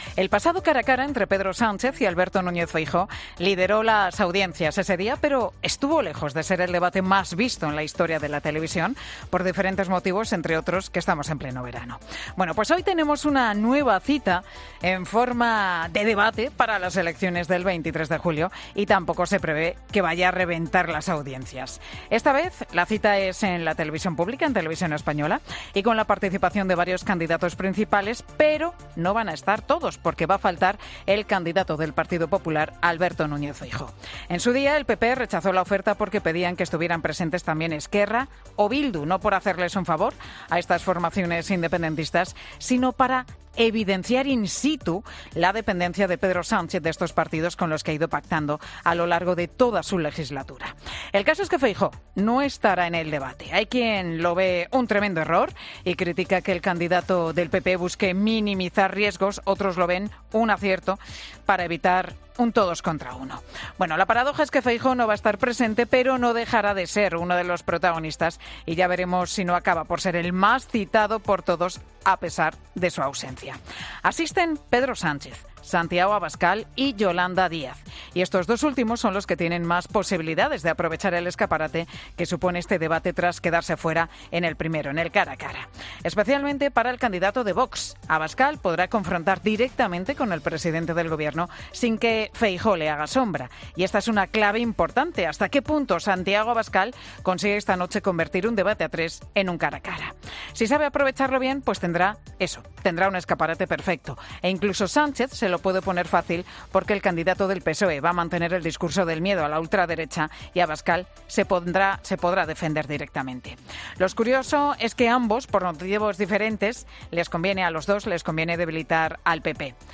Monólogo de Pilar García Muñiz
Escucha el monólogo de Pilar García Muñiz de este miércoles 19 de julio en 'Mediodía COPE', en el que analiza qué se juega cada partido en el debate de esta noche